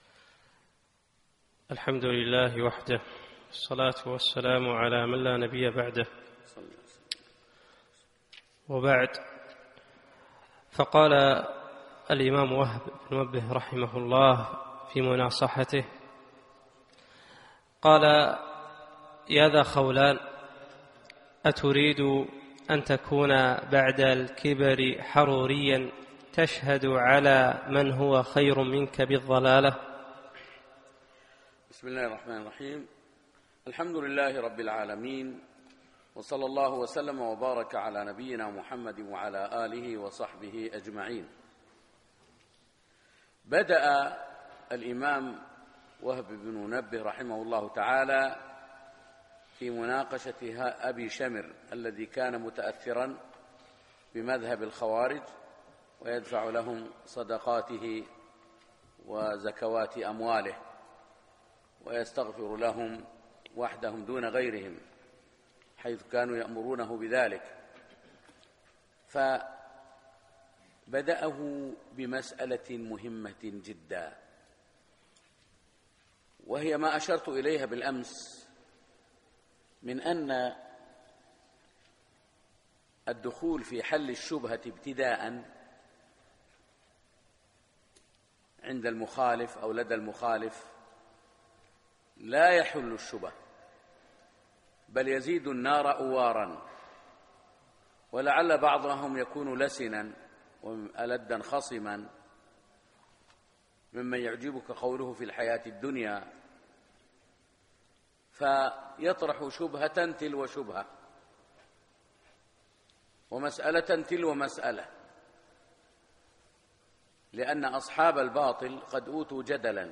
فجر السبت 1 4 2017 مسجد صالح الكندري صباح السالم